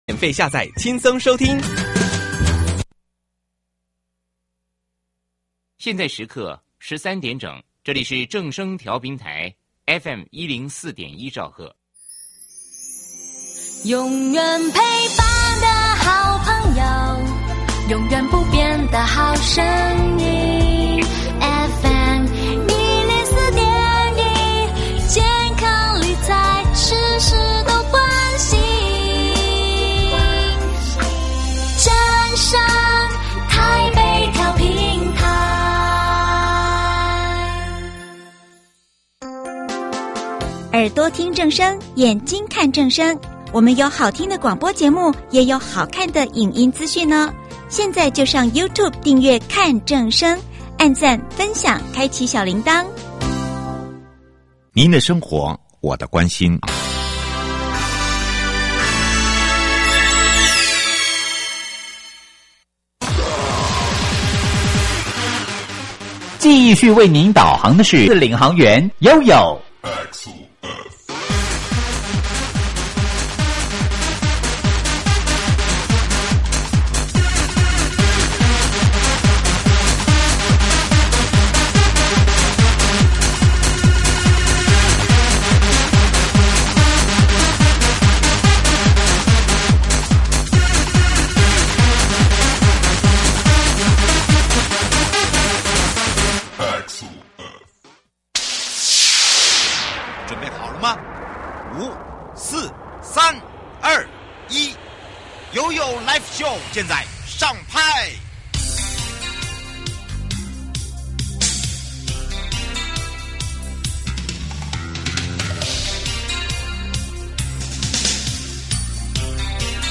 雲林縣過去在人行空間、校園通學道與高齡友善通行上有不少挑戰，但透過「提升道路品質計畫」與「人本交通建設」，不但打造安全人行道，還結合智慧化宣導與交通教育，全面守護我們的孩子與長者。我們今天特別邀請雲林縣交通工務局汪令堯局長，來跟大家分享雲林的努力與成果。